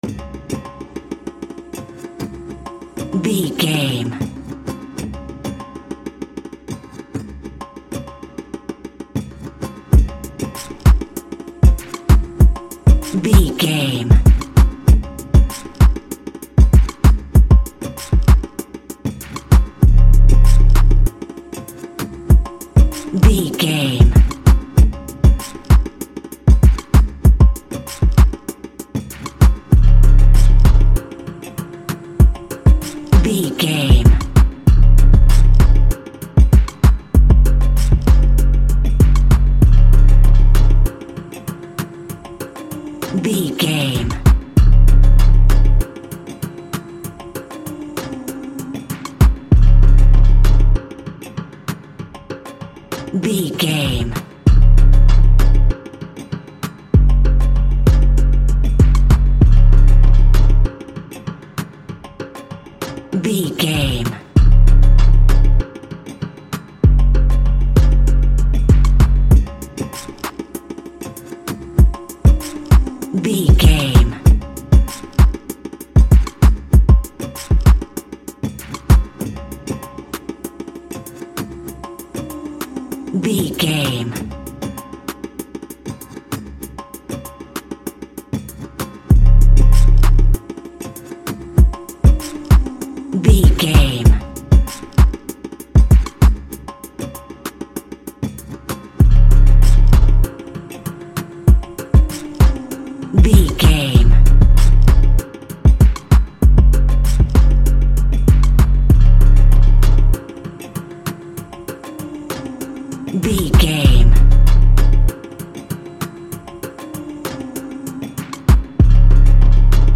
Ionian/Major
aggressive
intense
driving
energetic
dark
drums